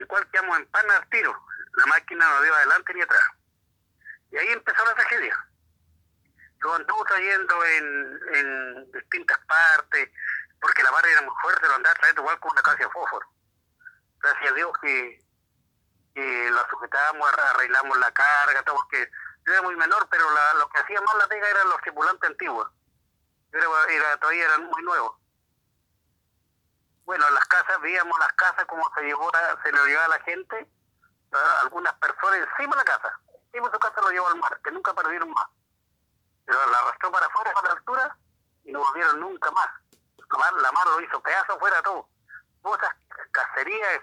Dos sobrevivientes del megaterremoto de 1960 contaron sus testimonios sobre la experiencia que vivieron en el mayor evento sísmico que se ha registrado a lo largo de la historia, el cual hoy conmemora seis décadas desde su ocurrencia.
Sobreviviente-1.m4a